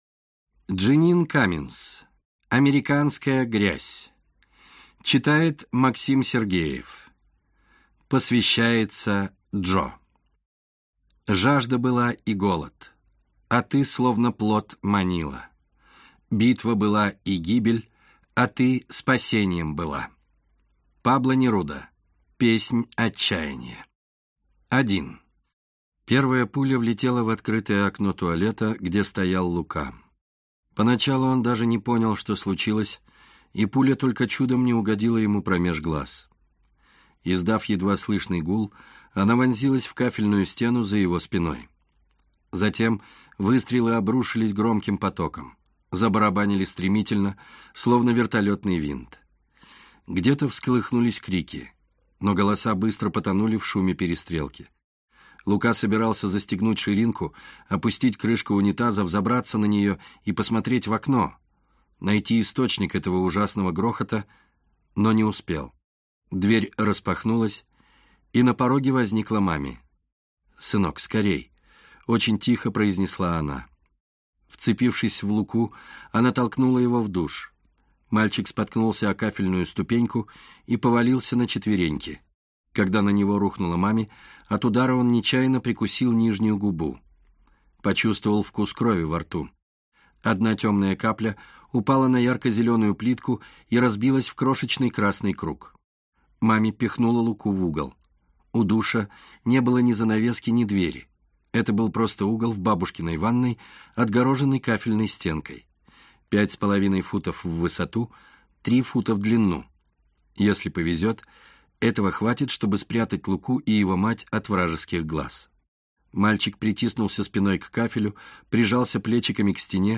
Аудиокнига Американская грязь | Библиотека аудиокниг